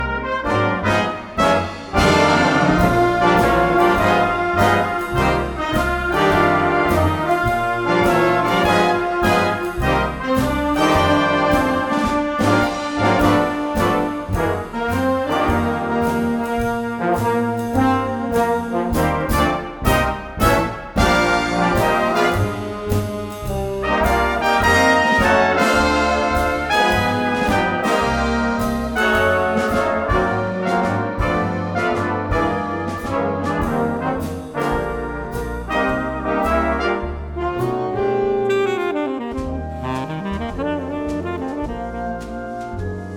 爵士音樂、發燒音樂